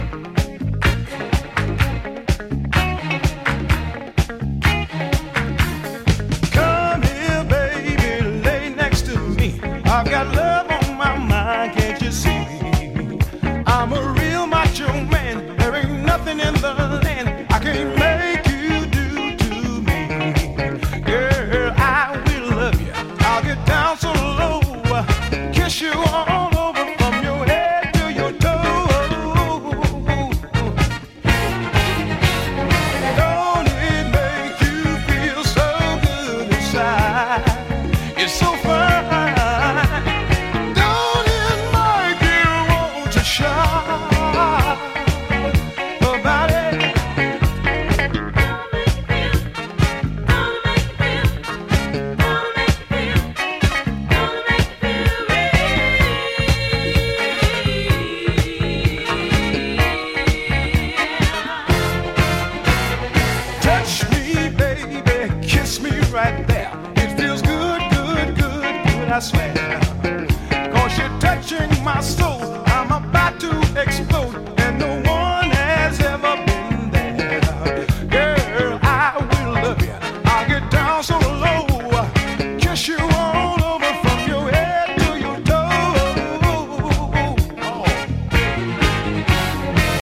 A killer deep-disco and very rare collectable single